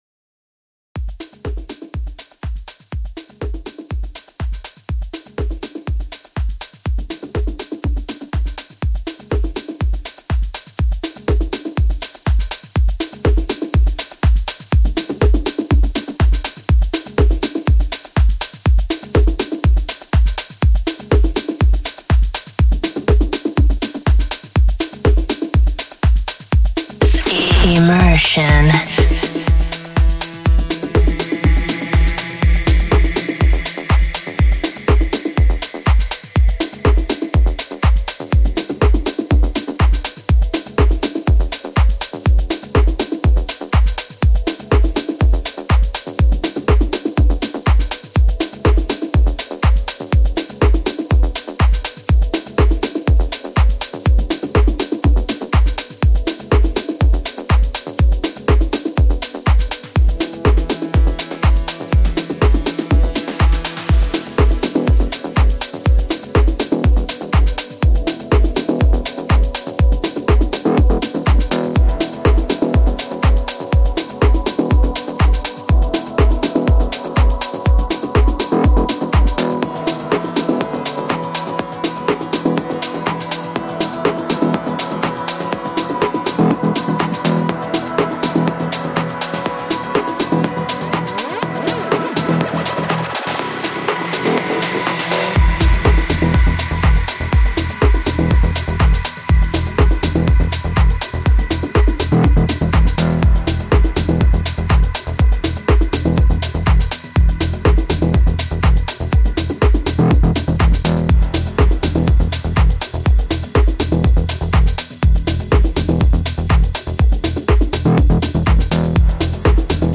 progressive house & techno